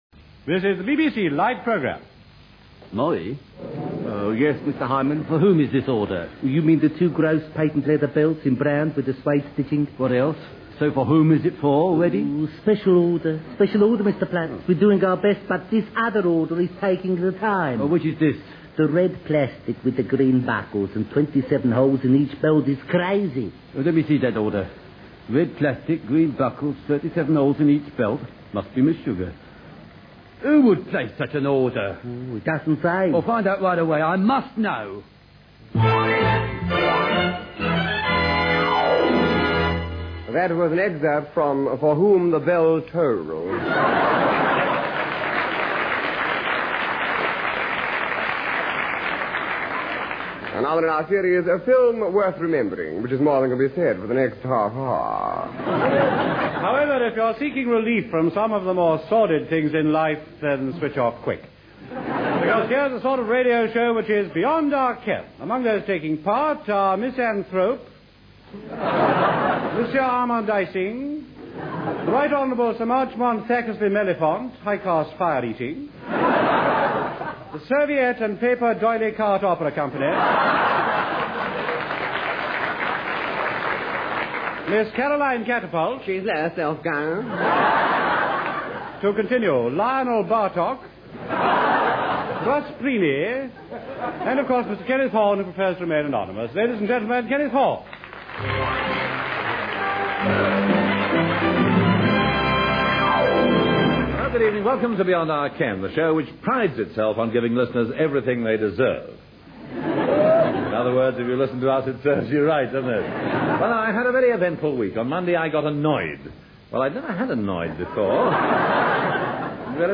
Beyond Our Ken featured Kenneth Horne with a regular cast performing short sketches.
Hugh Paddick, Betty Marsden, Bill Pertwee and Kenneth Williams
Musical interludes were performed by The Frazer Hayes Four.